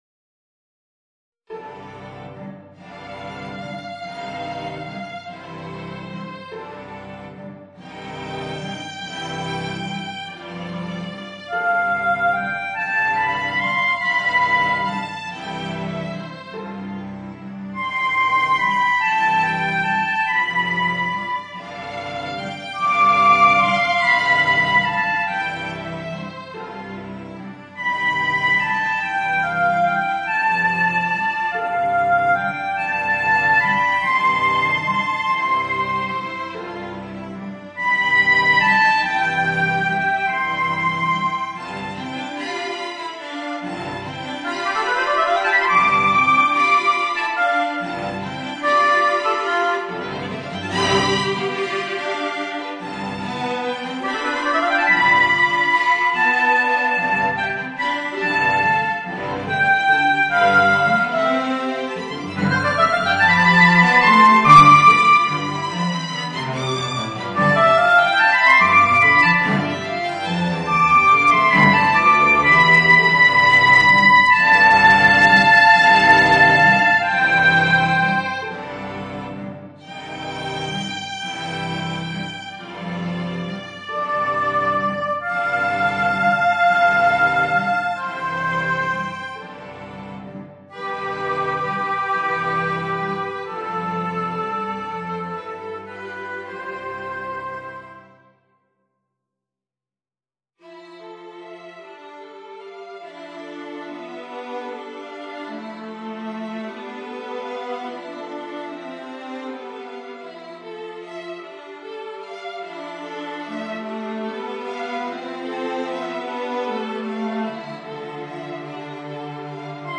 Voicing: Oboe, Violin, Viola and Violoncello